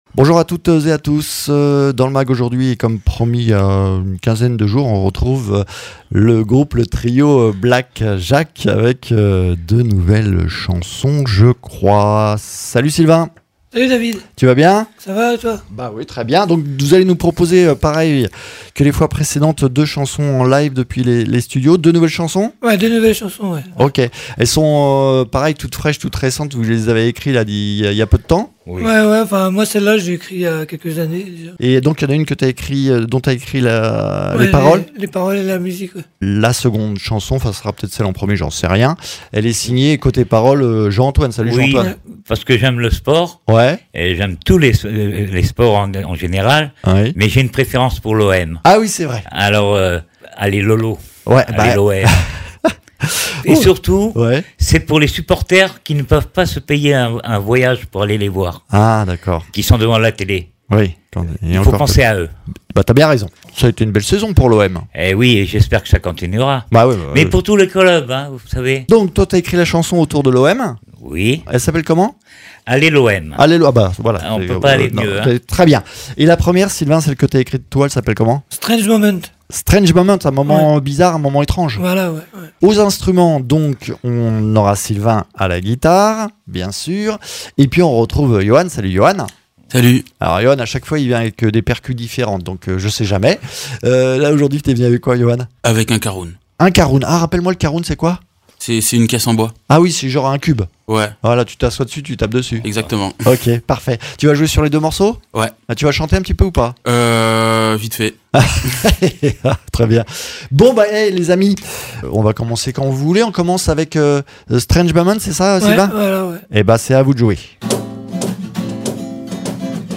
Le groupe Black Jacques revient pour deux nouvelles chansons en live , dont une sur l’Olympique de Marseille et surtout ses supporters
Interviews